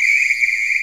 Cx whistle.wav